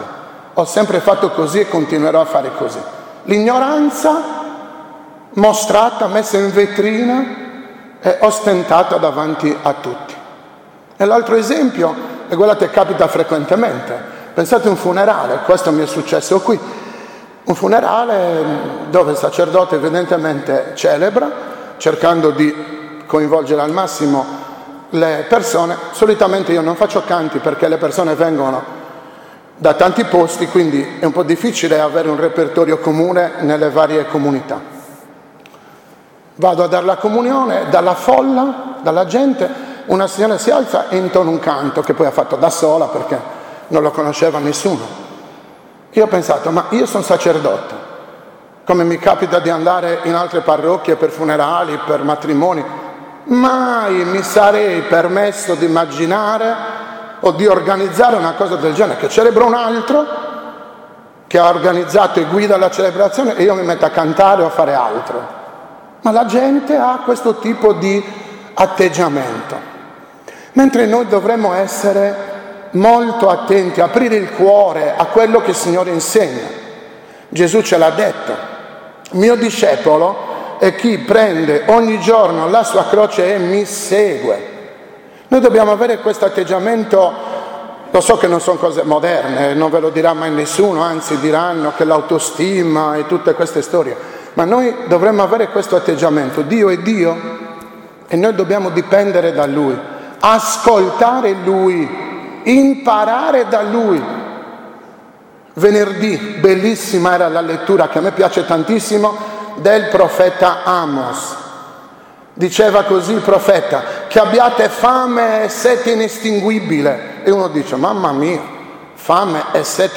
2018 8.7 OMELIA DELLA XIV DOMENICA PER ANNUM
2018-OMELIA-DELLA-XIV-DOMENICA-PER-ANNUM-2.mp3